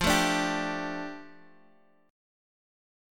F6add9 Chord
Listen to F6add9 strummed